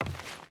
Wood Walk 1.ogg